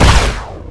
fire_photon4.wav